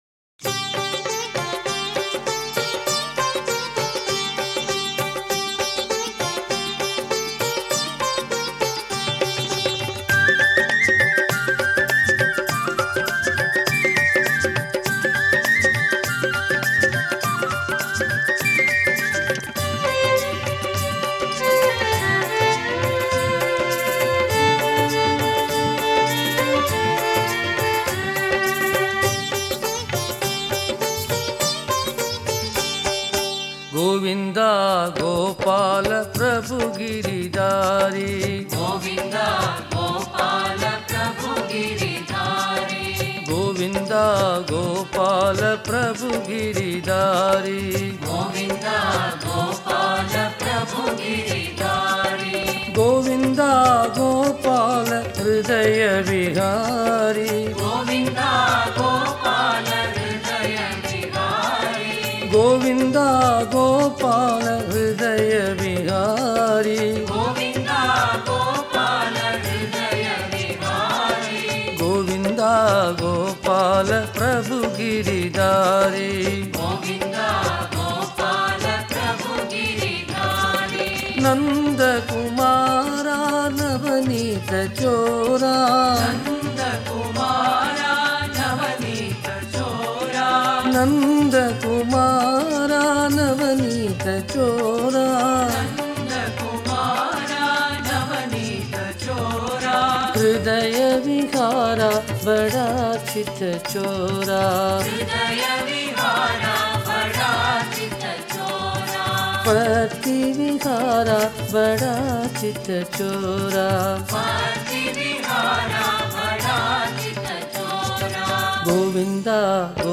Author adminPosted on Categories Krishna Bhajans